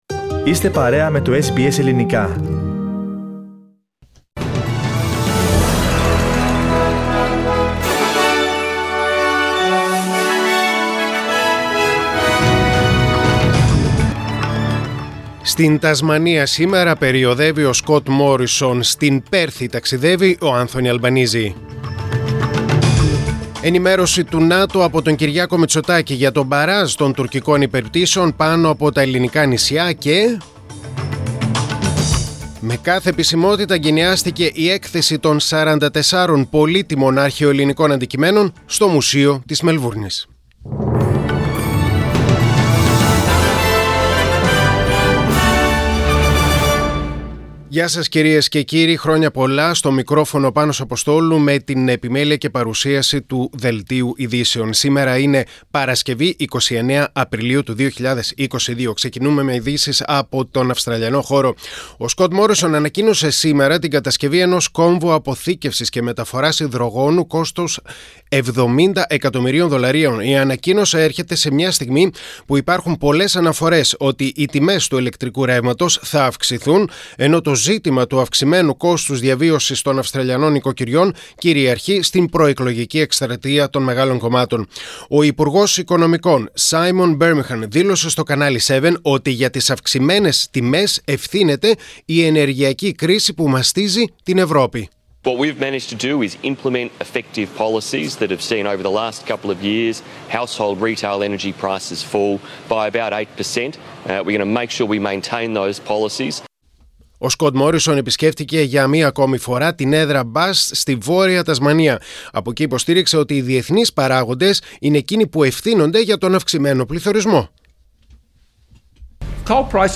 Δελτίο Ειδήσεων: Παρασκευή 29.4.2022